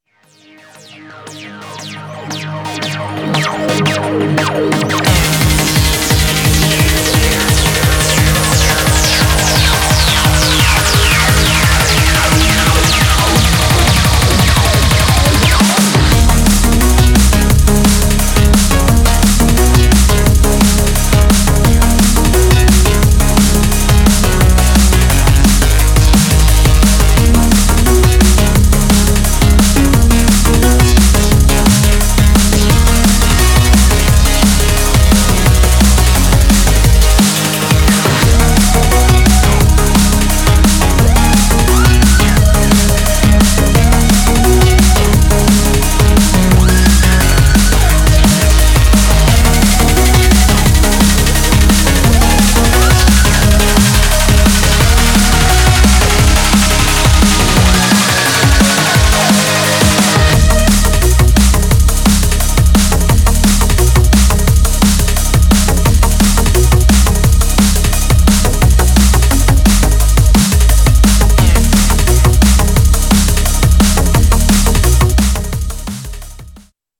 Styl: Drum'n'bass
* hraje velmi dobře